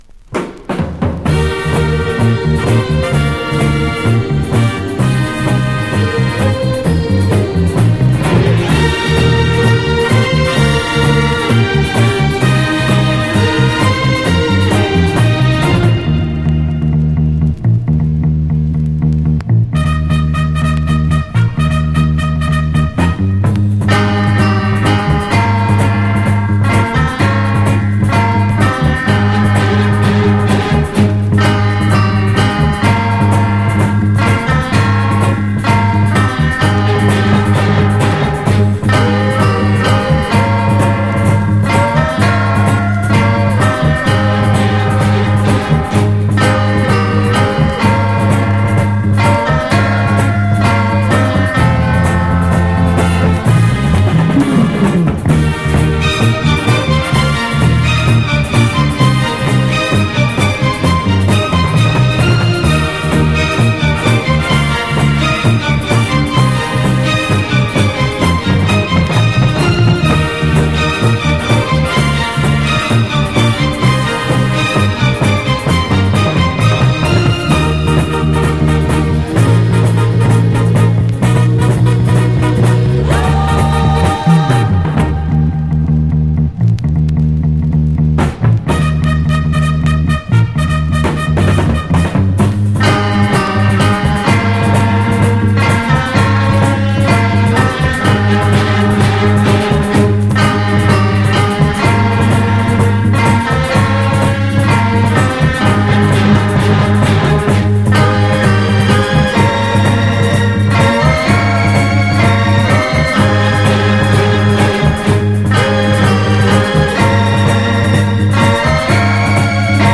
format: 7inch